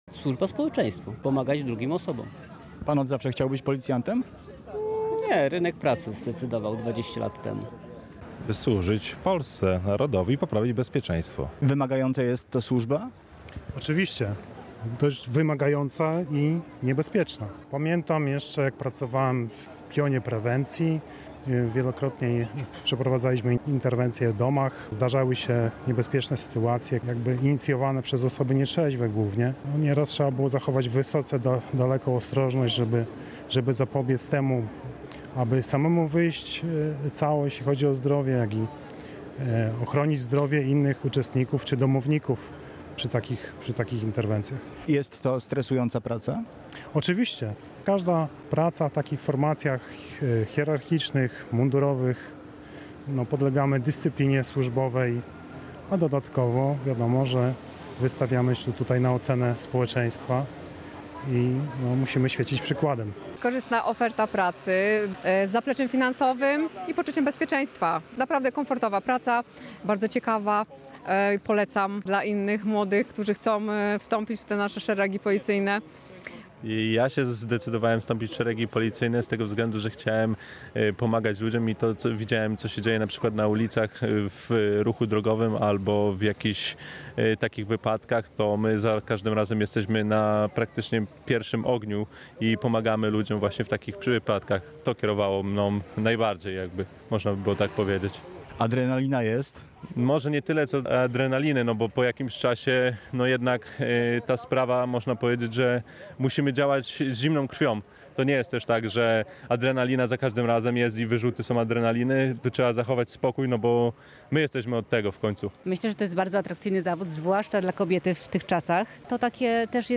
Uroczystości odbyły się na Placu Farnym, a poprzedziło je nabożeństwo w tamtejszym kościele.
Relacja